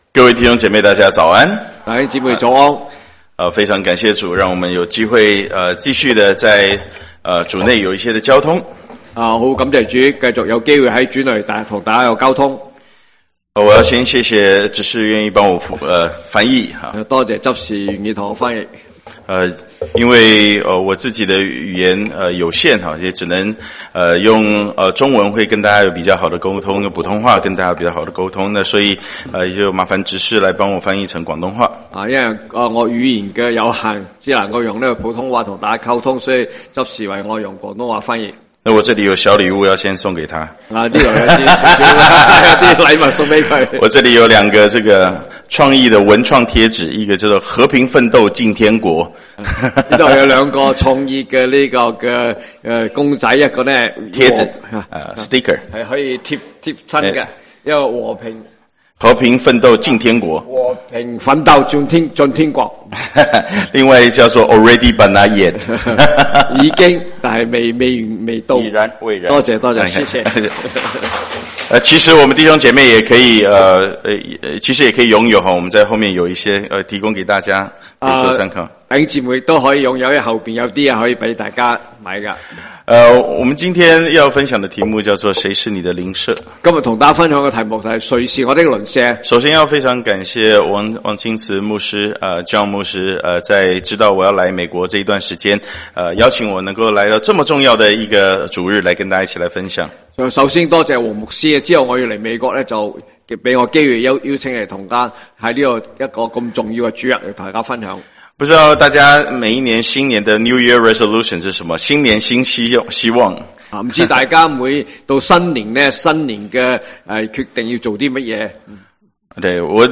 中文堂講道信息 | First Baptist Church of Flushing
Guest Speaker